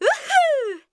cheers1.wav